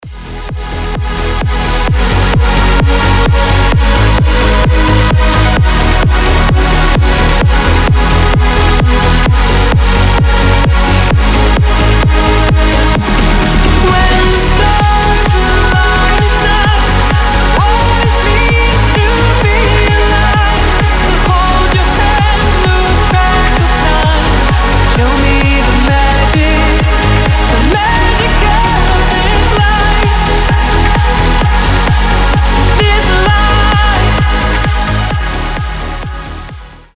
громкие
EDM
vocal trance
транс
Uplifting + vocal trance